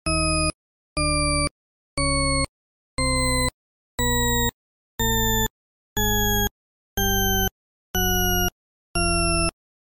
Golden responding to high-pitch sounds🔉